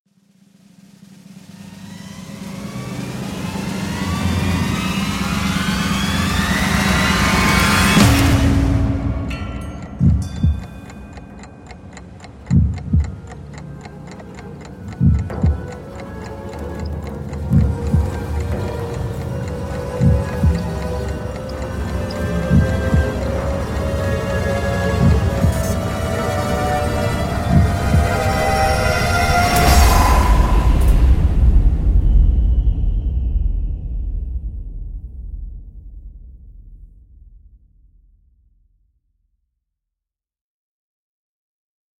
Звуки ожидания
Звук ожидания верного ответа в телешоу